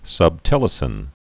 (sŭb-tĭlĭ-sĭn)